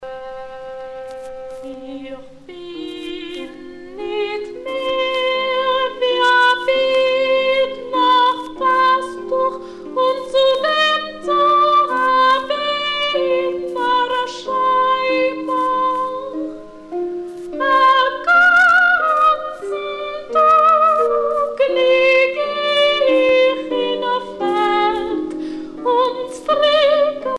Cantilations bibliques